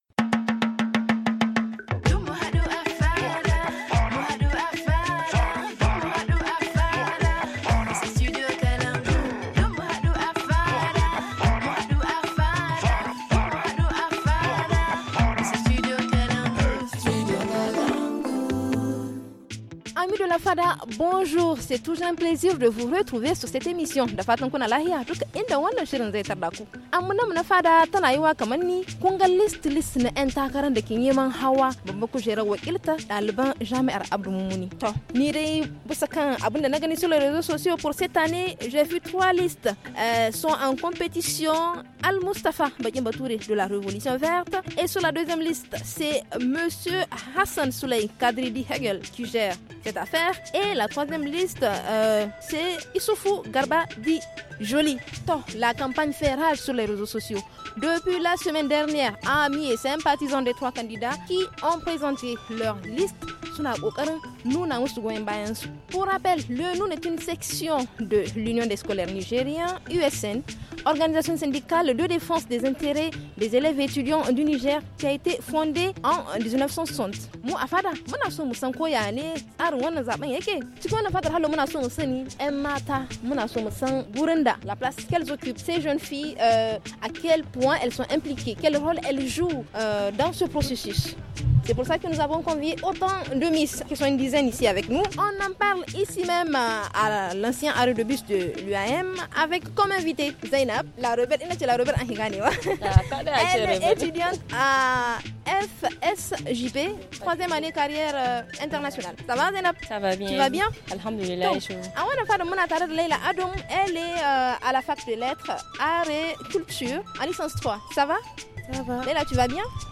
Pour en parler nous sommes installés au niveau de l’ancien arrêt de bus avec nos hôtes